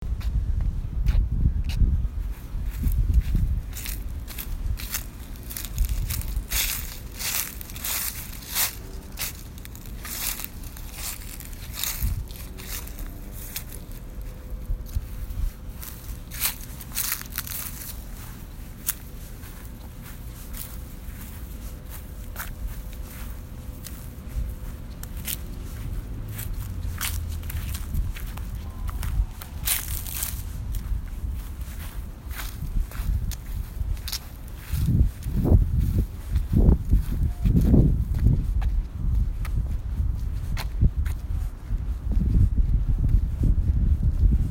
Field Recording five
You will hear my feet walking from the pavements onto the grass to walk on the leaves that were still left over from the fall, then back onto the pavement. The sounds are very distinct in themselves, the pavement is more of a scuffing hard sound while the ground sounded soft under my feet and the leaves crunched as they were crushed.
Leaves.mp3